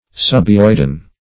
Search Result for " subhyoidean" : The Collaborative International Dictionary of English v.0.48: Subhyoidean \Sub`hy*oid"e*an\, a. (Anat.
subhyoidean.mp3